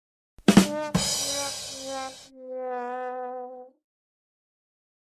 Rim shot with wah wah wah